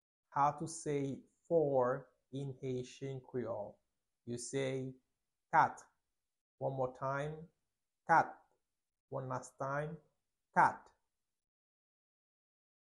7.How-to-say-four-in-haitian-creole-–-Kat-pronunciation-1.mp3